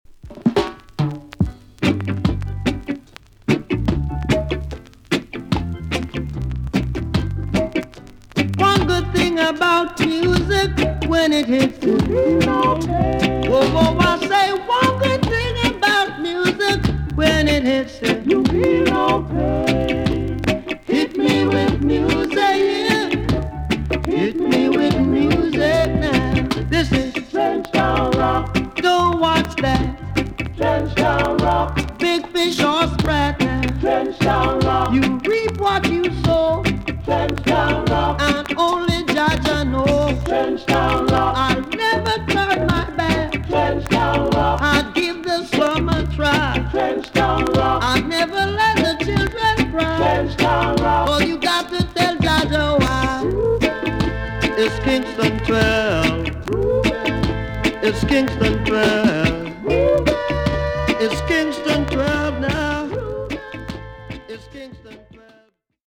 TOP >LP >VINTAGE , OLDIES , REGGAE
A.SIDE VG+ 少し軽いチリノイズが入ります。